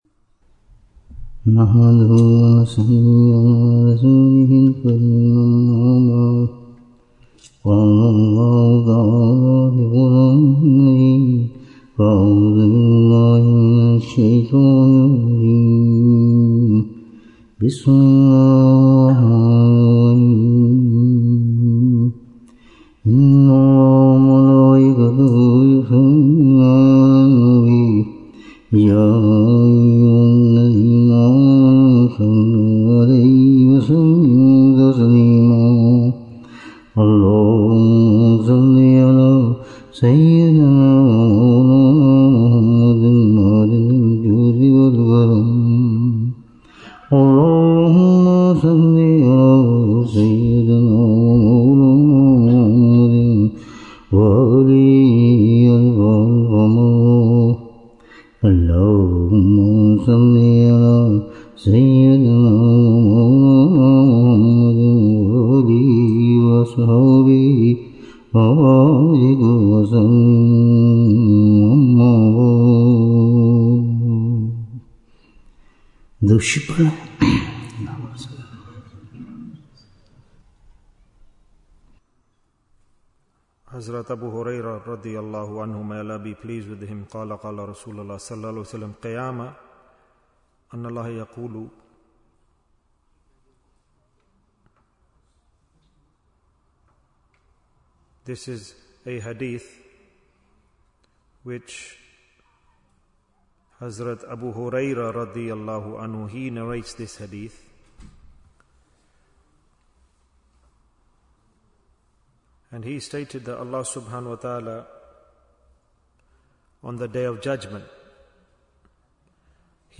Jewels of Ramadhan 2025 - Episode 43 - The Last Asharah in Manchester Bayan, 46 minutes27th March, 2025